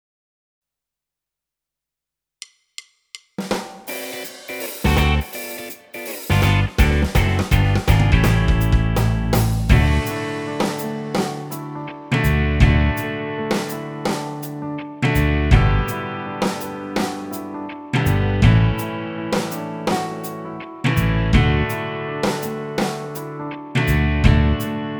Produkt zawiera utwór w wersji instrumentalnej oraz tekst.